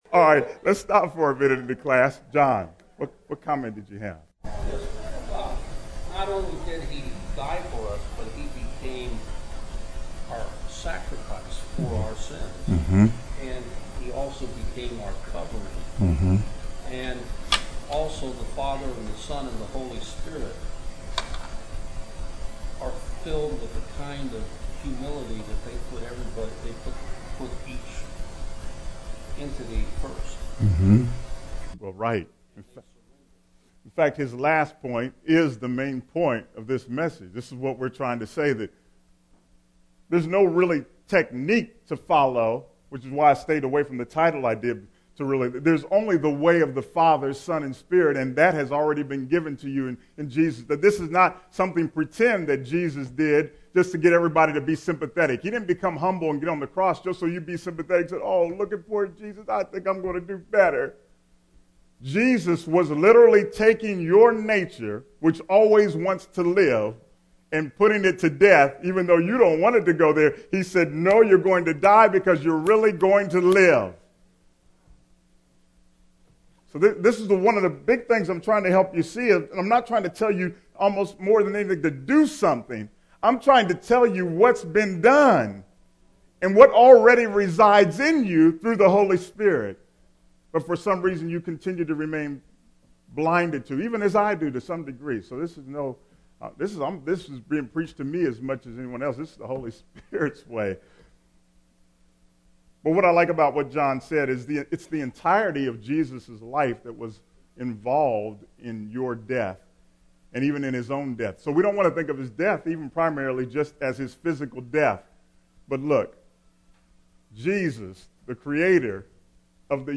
Sermon: With “Three-Dom” Comes One Great “Response-Ability”, Part B